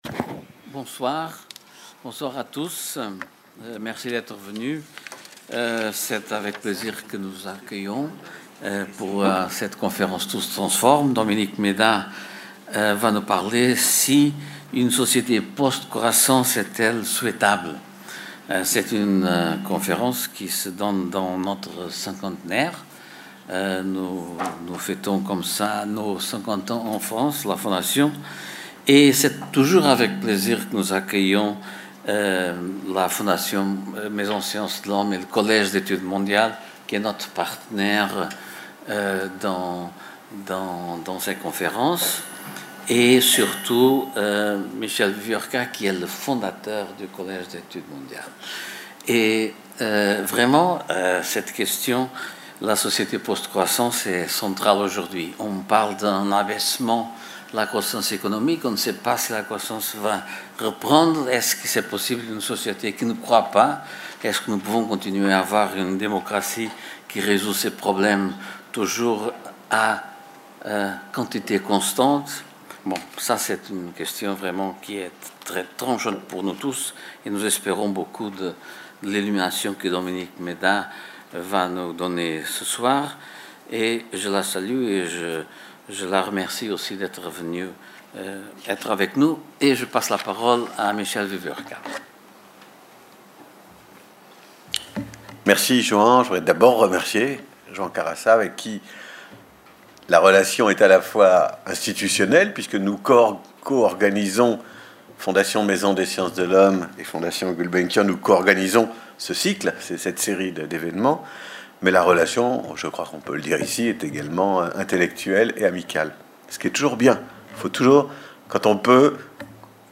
Conférence co-organisée par le Collège d'études mondiales et la Fondation Calouste Gulbenkian. Conférence donnée par Dominique Méda, titulaire de la chaire Écologie, travail et emploi du Collège d'études mondiales.